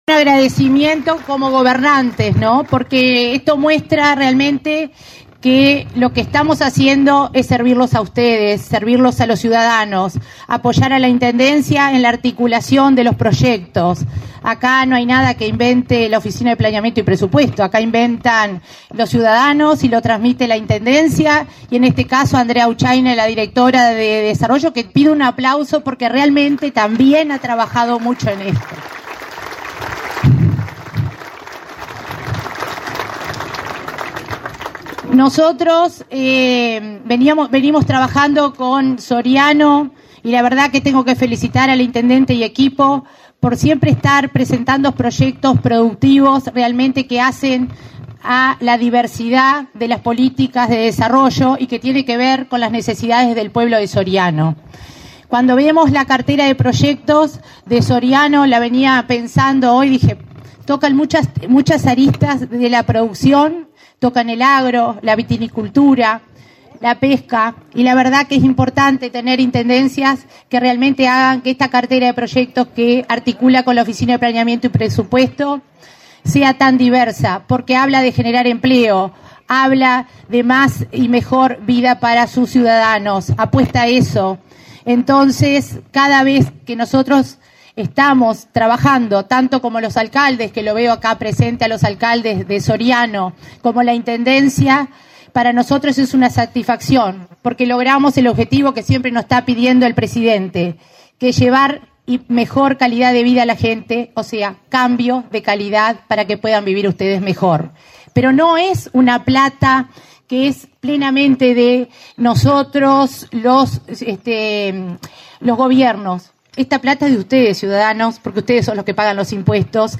Conferencia de prensa por la inauguración de planta de acopio de pescado en Villa Soriano
Con la presencia del presidente de la República, Luis Lacalle Pou, se realizó, este 18 de octubre, la inauguración de una planta de acopio de pescado con cámara de frío, destinada a la producción de los pescadores artesanales, en la localidad de Villa Soriano. Participaron el intendente Guillermo Besozzi; el subsecretario de Ganadería, Juan Ignacio Buffa, y la coordinadora del Área de Descentralización de la Oficina de Planeamiento y Presupuesto, María de Lima.